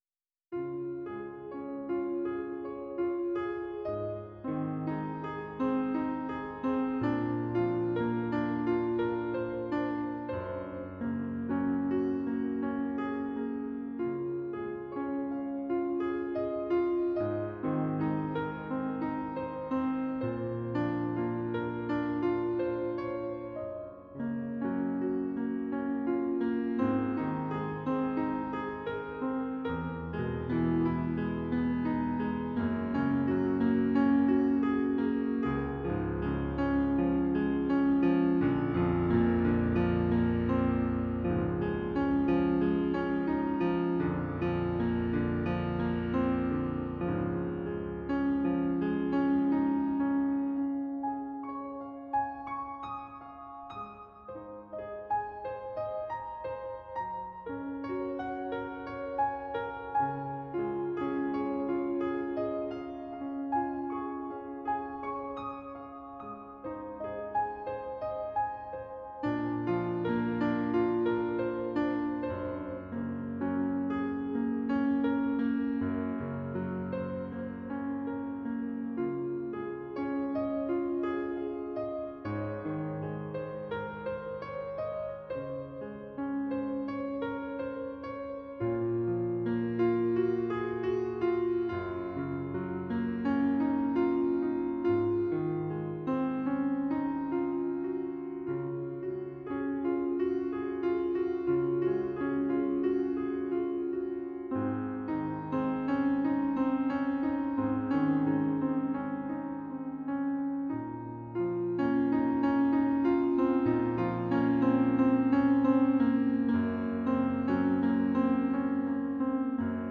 Piano Solo Music Composition